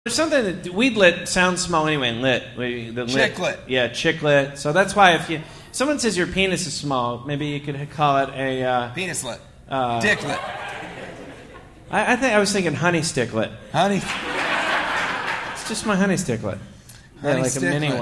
Live from Legends